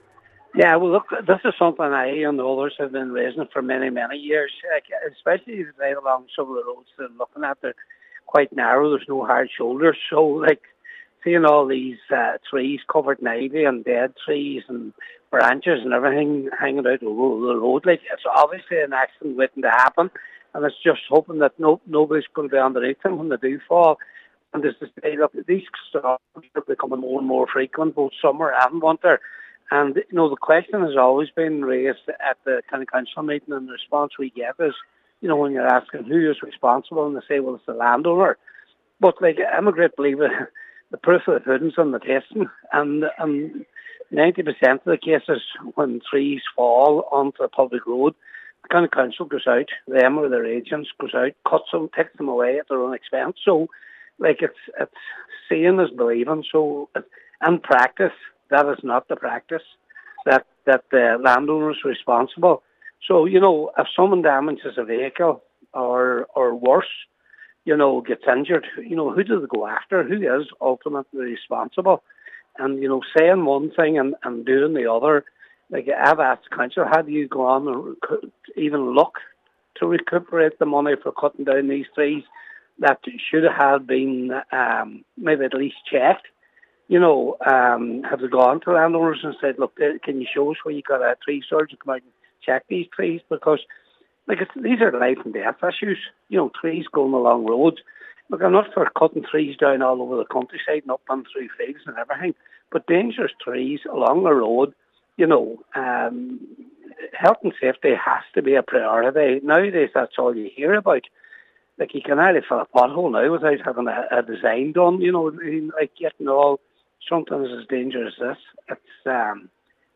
However, Councillor Patrick McGowan says it is the council that pays for cleanup operations after stormy weather